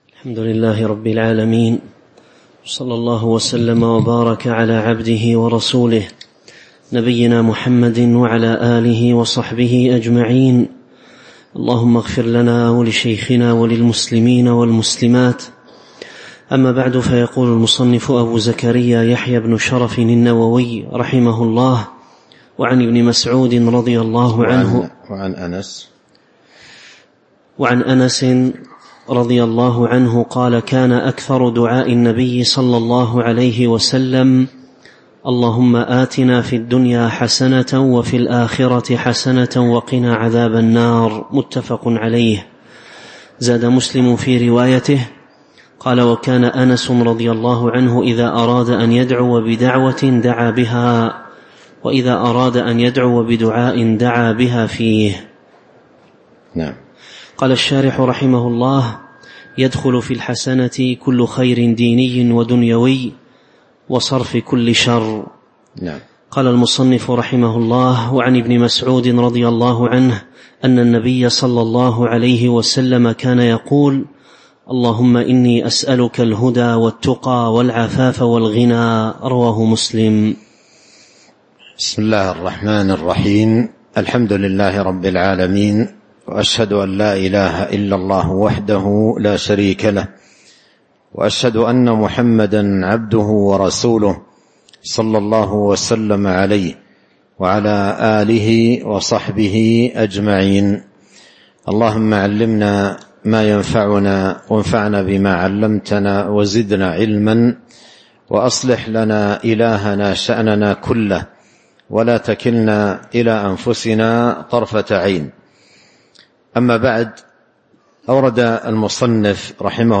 تاريخ النشر ٣ رمضان ١٤٤٥ هـ المكان: المسجد النبوي الشيخ: فضيلة الشيخ عبد الرزاق بن عبد المحسن البدر فضيلة الشيخ عبد الرزاق بن عبد المحسن البدر باب فضل الدعاء (03)